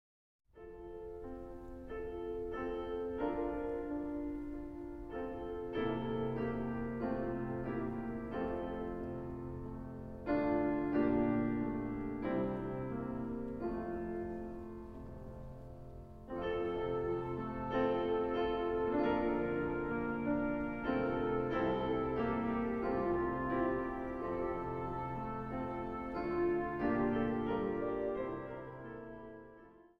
Psalms, Hymns and Psalters
orgel
piano
harp
viool.
Zang | Mannenkoor